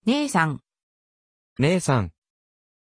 Pronunciation of Nathan
pronunciation-nathan-ja.mp3